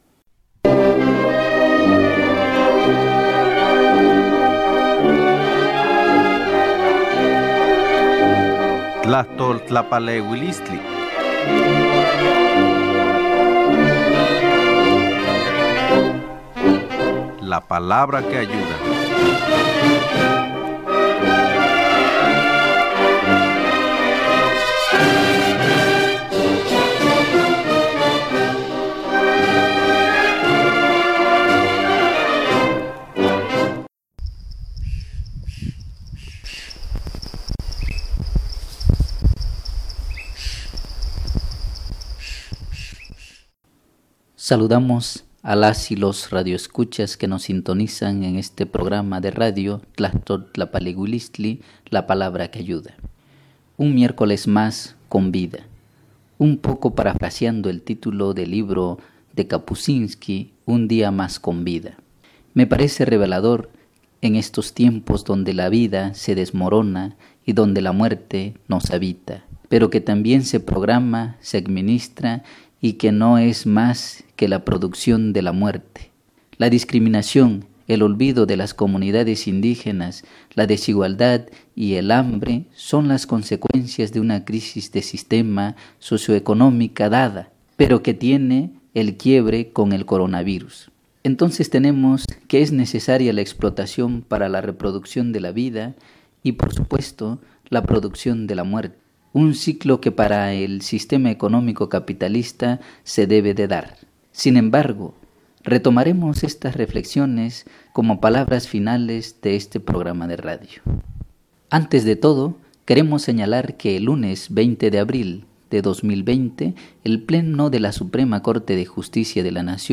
Escucha: Programa de radio: Tlajtoltlapalehuilistli “La Palabra que Ayuda”.